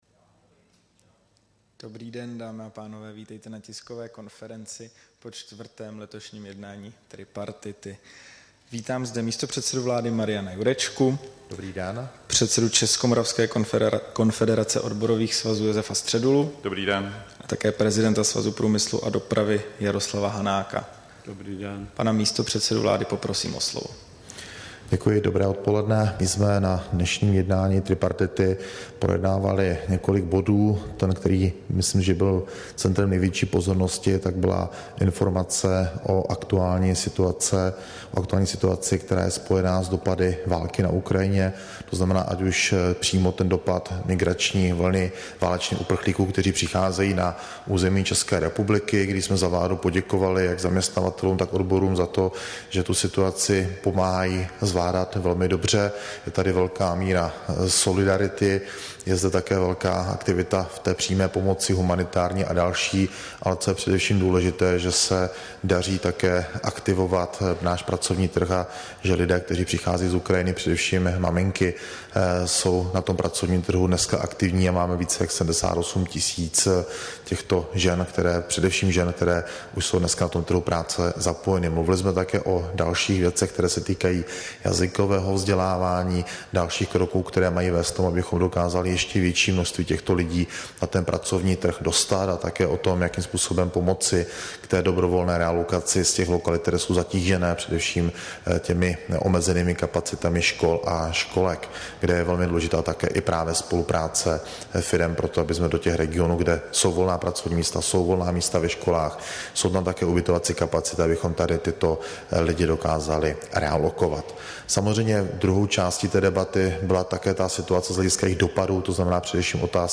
Tisková konference po jednání tripartity, 21. června 2022